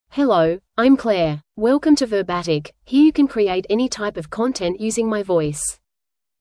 Claire — Female English (Australia) AI Voice | TTS, Voice Cloning & Video | Verbatik AI
FemaleEnglish (Australia)
Claire is a female AI voice for English (Australia).
Voice sample
Listen to Claire's female English voice.
Female